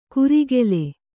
♪ kurigele